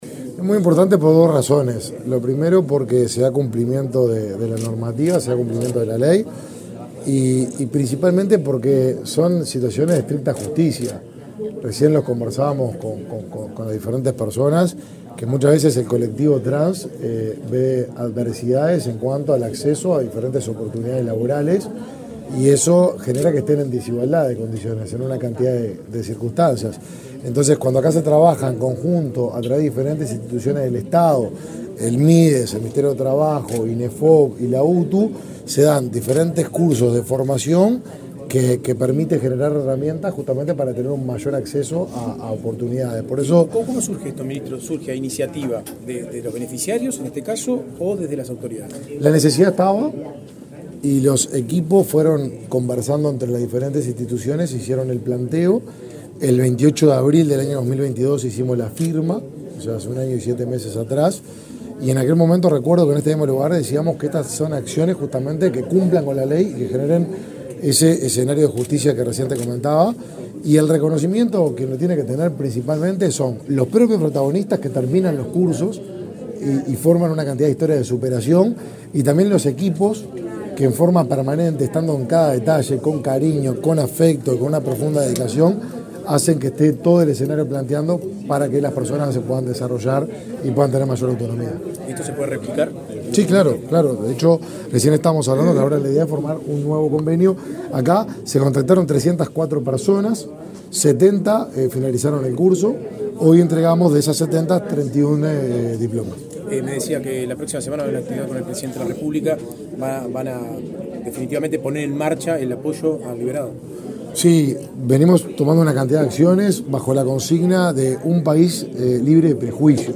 Declaraciones del ministro de Desarrollo Social, Martín Lema
El ministro de Desarrollo Social, Martín Lema, participó del acto de entrega de diplomas sobre habilidades transversales para la empleabilidad de